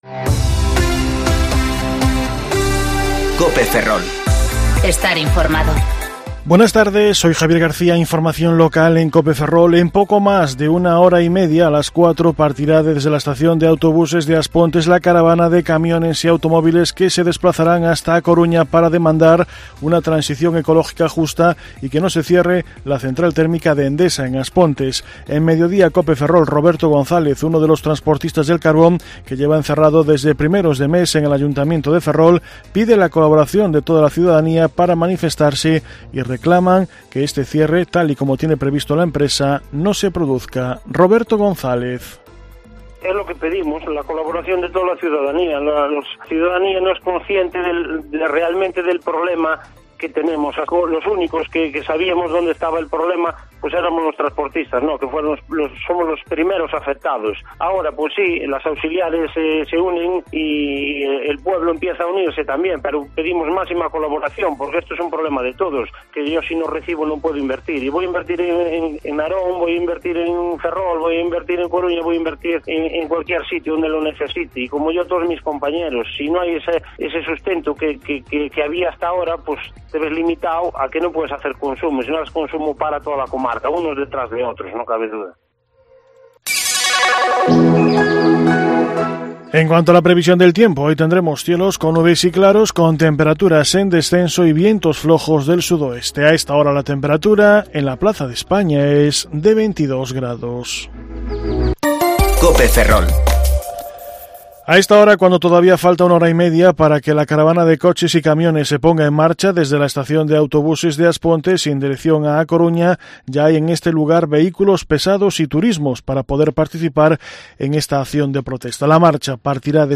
Informativo Mediodía Cope Ferrol 1/10/2019 (De 14.20 a 14.30 horas)